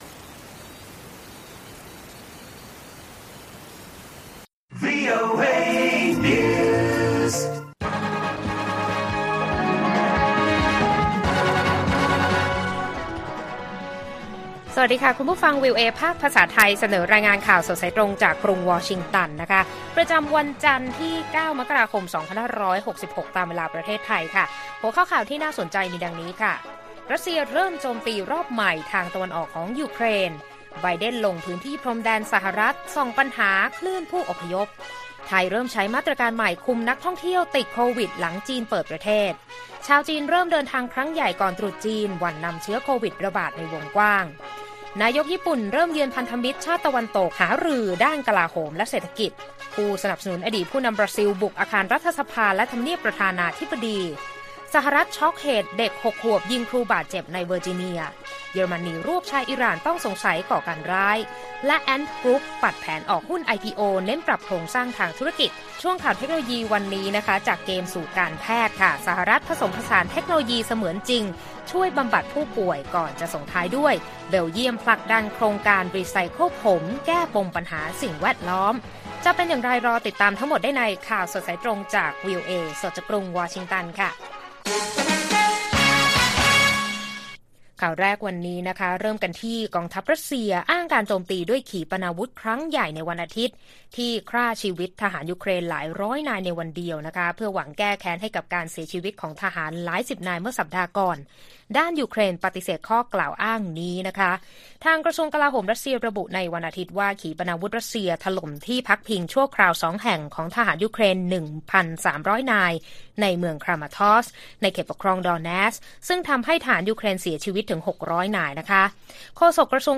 ข่าวสดสายตรงจากวีโอเอไทย 8:30–9:00 น. วันที่ 9 ม.ค. 66